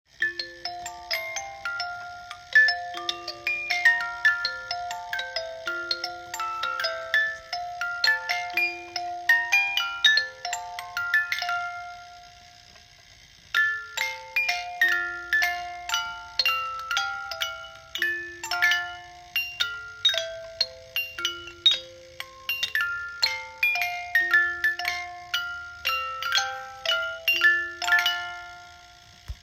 Type Barnyard, Music Box
When the lid to the pump house is lifted to access the ink pot within, it actuates a music box.
A clockwork motor powers the music box and rotates the glass spiral by pulley and belt.
musicbox.m4a